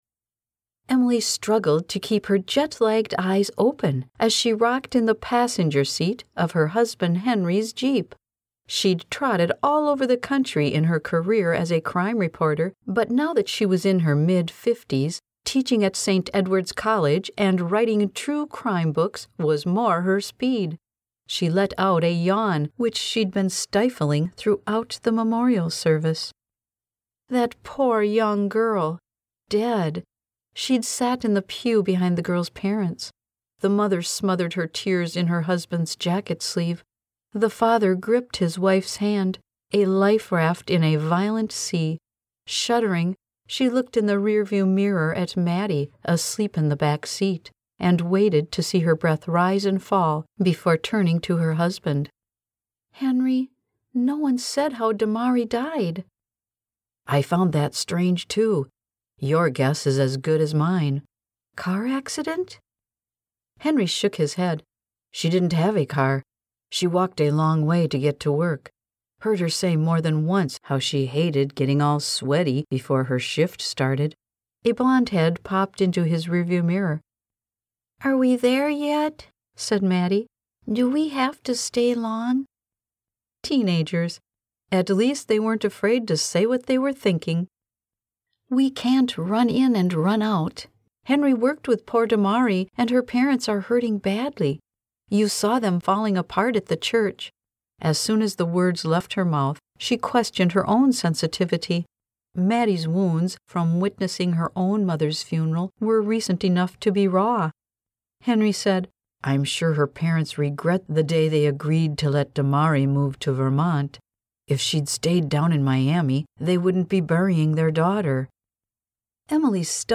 Audiobook Narrator and Voiceover Artist
A warmhearted, authentic and vibrant voice.
My happy place is tucked away in my studio narrating my latest book.